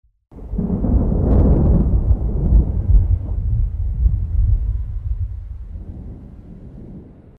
thunder5.mp3